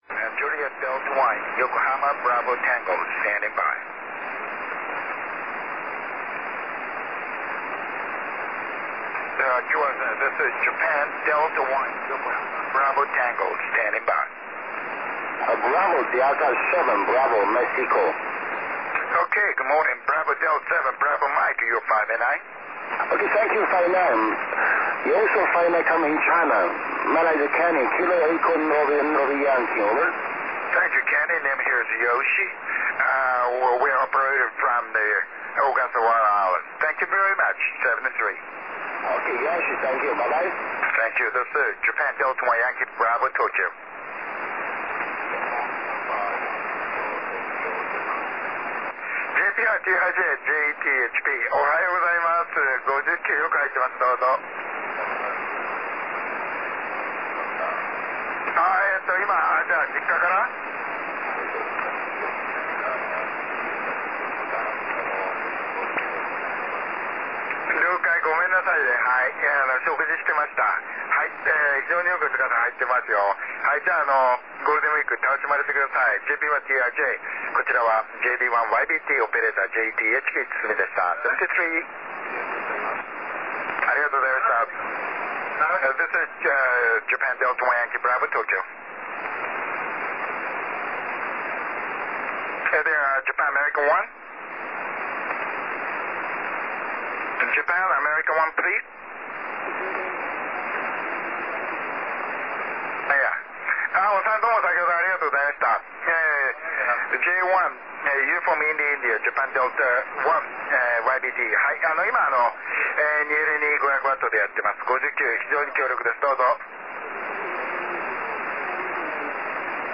21.230MHz SSB